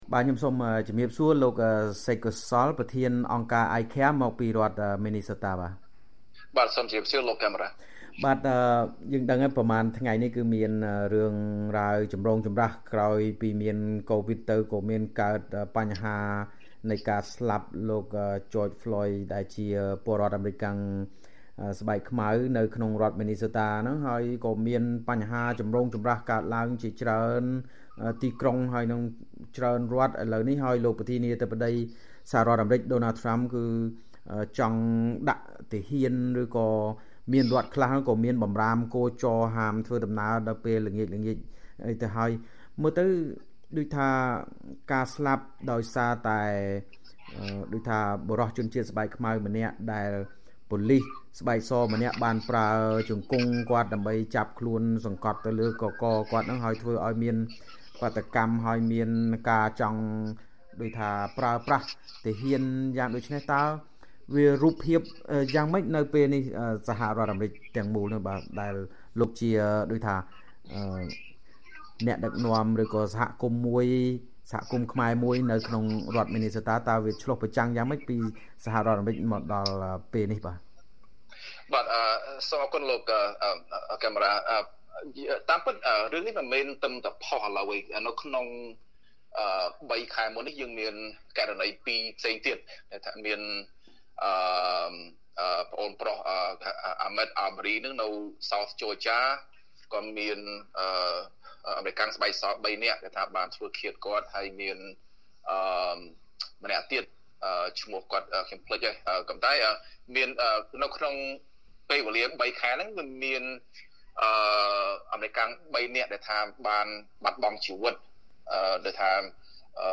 បទសម្ភាសន៍ VOA៖ ពលរដ្ឋខ្មែរអាមេរិកាំងបកស្រាយពីអំពើយុត្តិធម៌និងហិង្សាទាក់ទិននឹងការស្លាប់របស់លោក Floyd